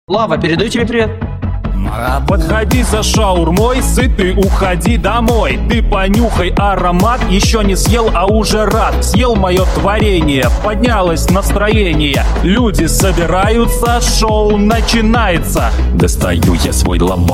(Mashup)